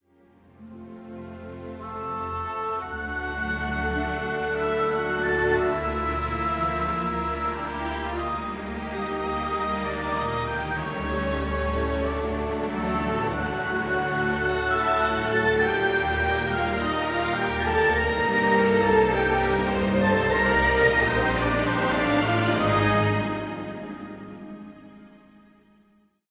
Genre Film score
Key G major / B minor
Time signature 4/4